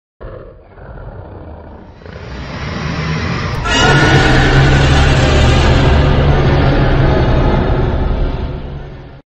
Dragon Roar 4 Sound Effect Free Download
Dragon Roar 4